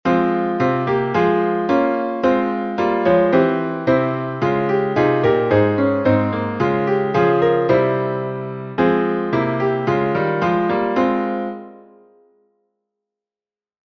Key: f minor